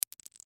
Звуки стука зубов
Кусочек зуба упал на асфальт